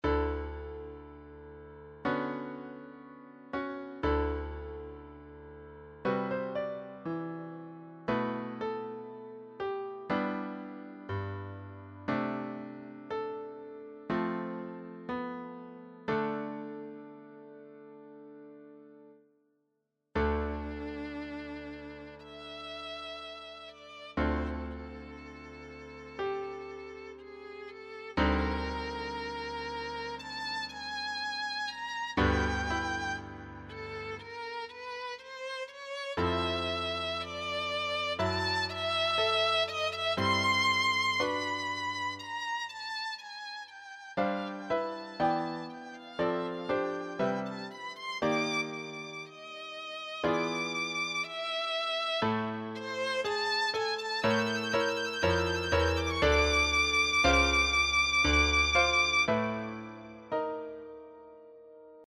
Besetzung Violine und Klavier